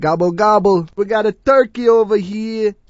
gutterball-3/Gutterball 3/Commentators/Louie/l_wegottaturkeyhere.wav at main
l_wegottaturkeyhere.wav